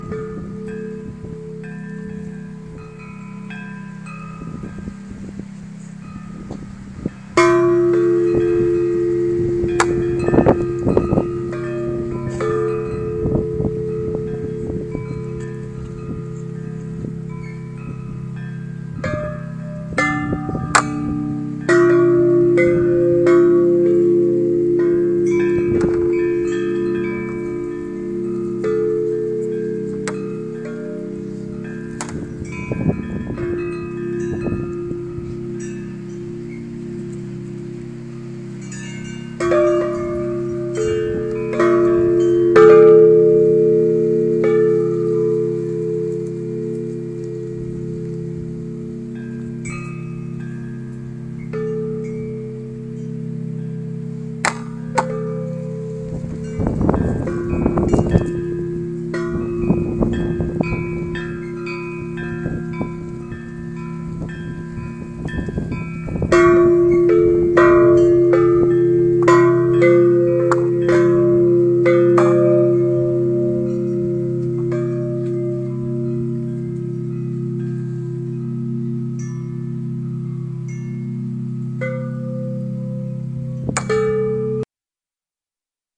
汪洋大风铃 FS 1 4 2011
描述：大风铃的原始声音文件...来自Wizard Wells的风
Tag: 风铃 原材料